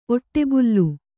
♪ oṭṭebullu